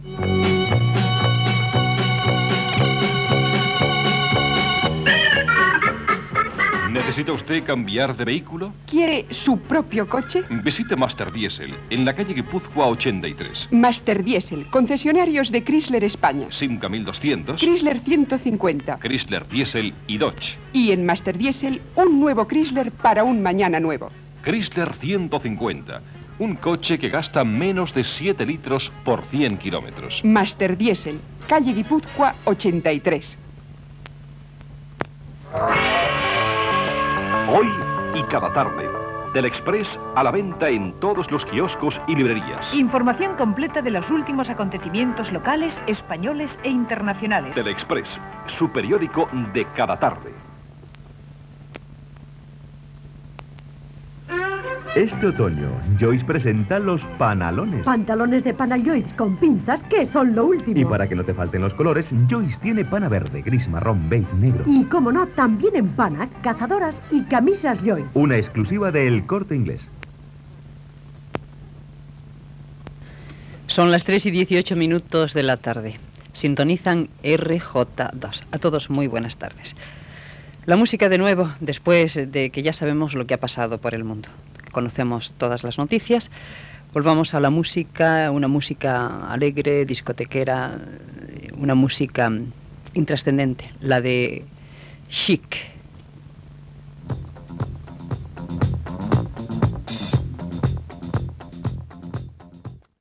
Publicitat, identificació i tema musical.
FM